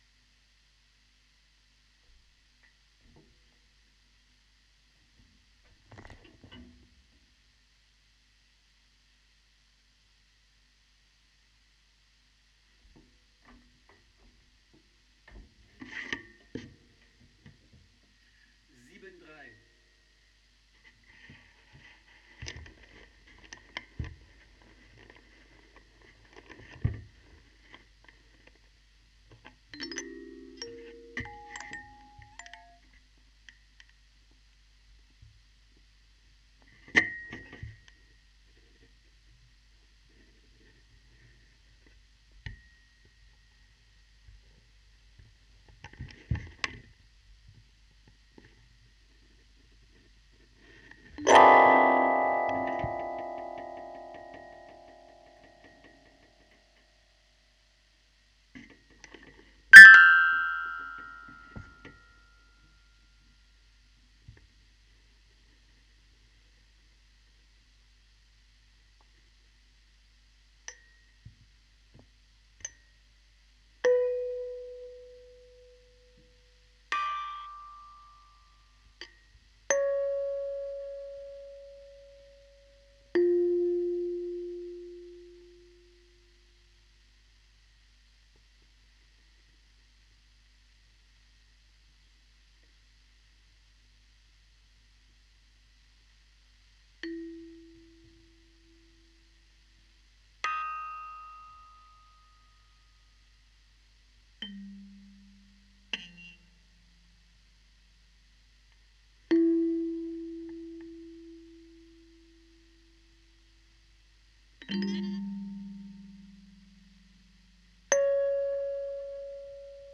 Audio 'sand' Remix 123 für die Teánola Spieluhr (2016) Für diese Remix-Version der Komposition werden die 4 Teile im Format A3 doppelseitig gedruckt.